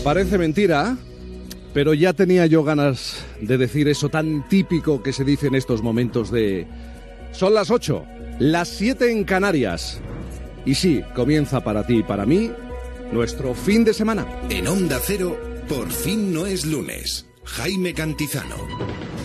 Presentació del primer programa i indicatiu
Entreteniment
FM